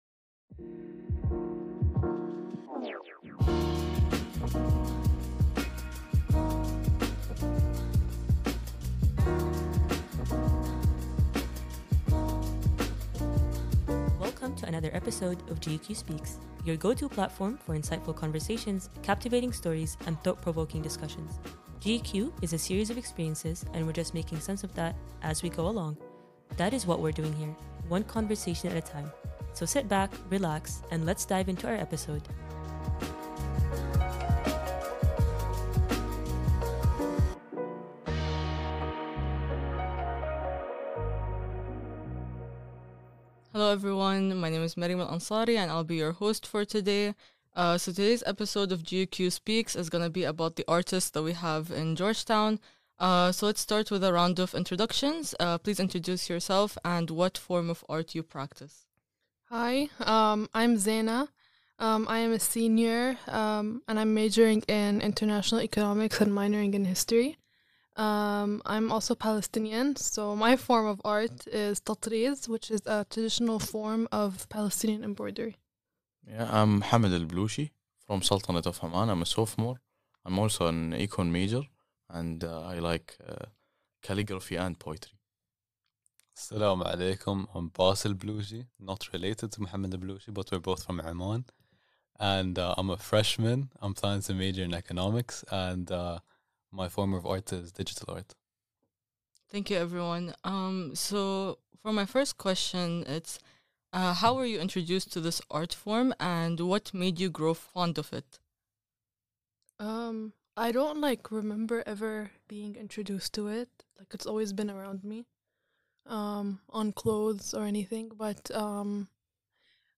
In this episode, podcast hosts discuss the art of different students studying at the Georgetown University Qatar campus.